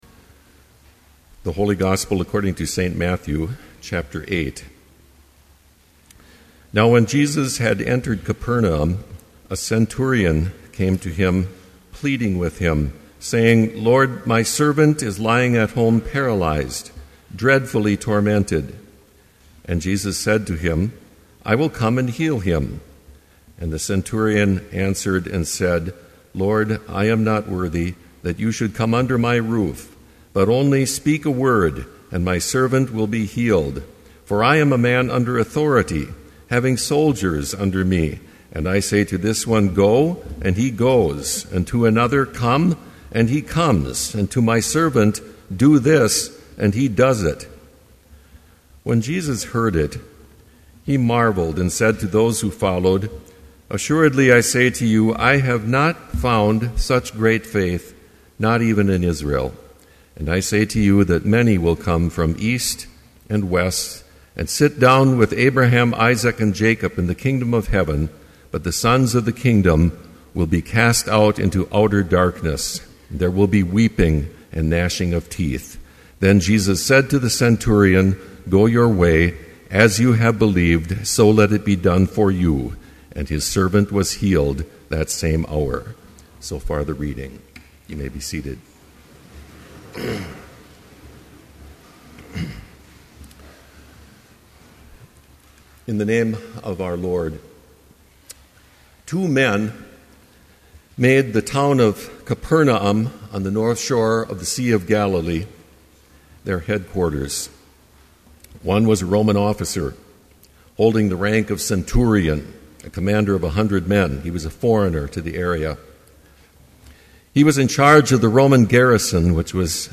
Complete service audio for Chapel - January 23, 2012